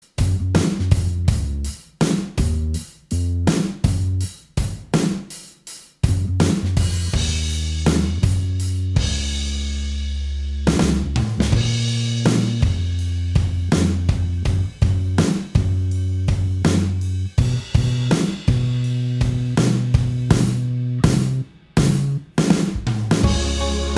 Minus All Guitars Pop (2010s) 3:43 Buy £1.50